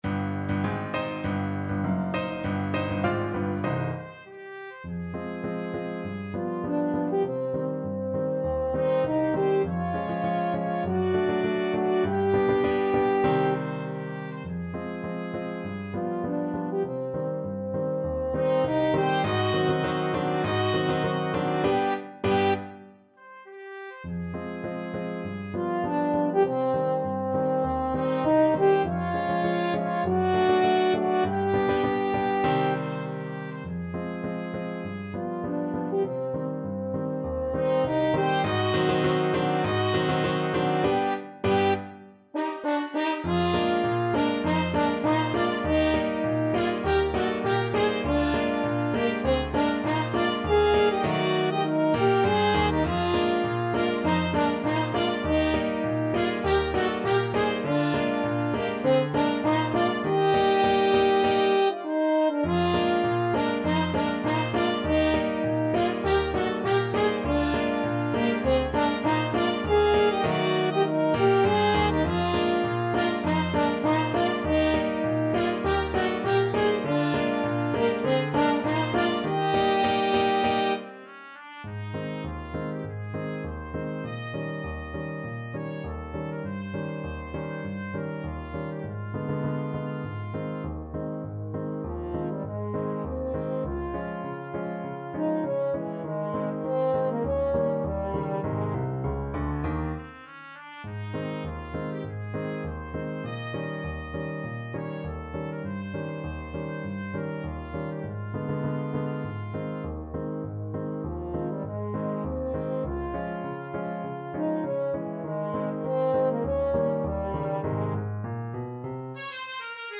Free Sheet music for Trumpet-French Horn Duet
TrumpetFrench Horn
2/2 (View more 2/2 Music)
Quick March = c. 100
C minor (Sounding Pitch) (View more C minor Music for Trumpet-French Horn Duet )
Classical (View more Classical Trumpet-French Horn Duet Music)